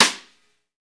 SNR XEXTS00L.wav